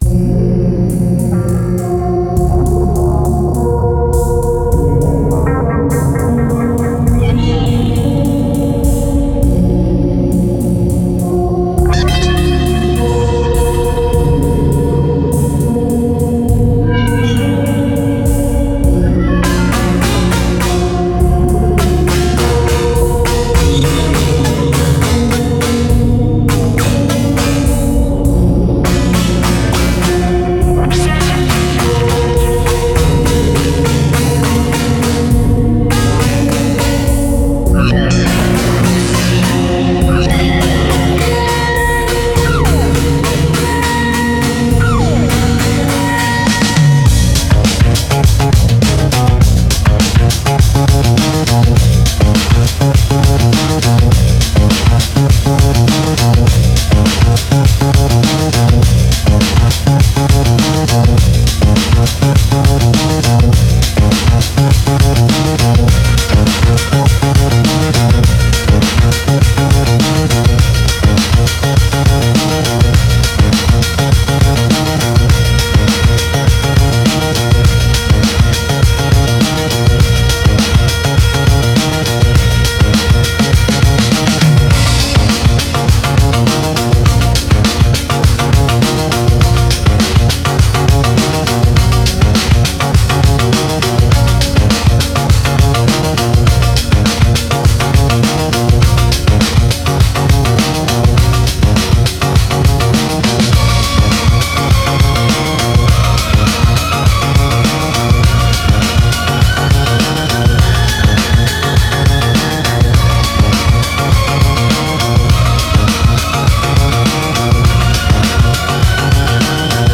Genre IDM